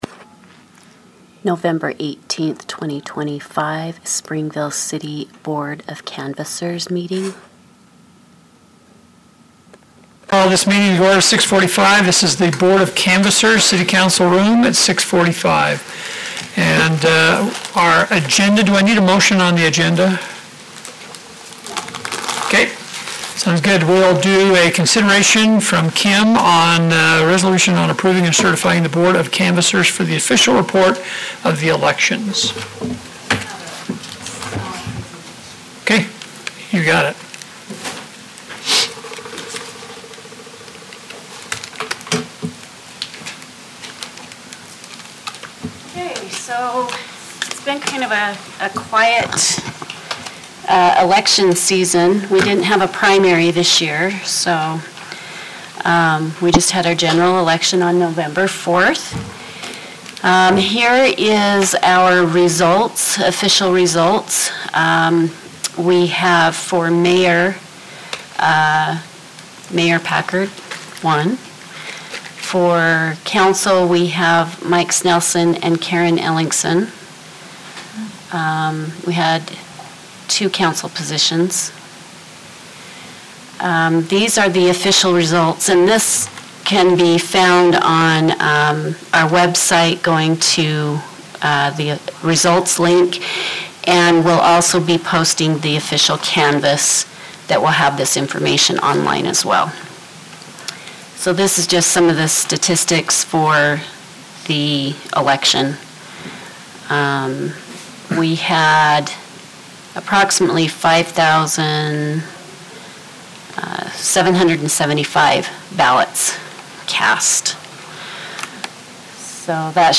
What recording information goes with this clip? City Council Room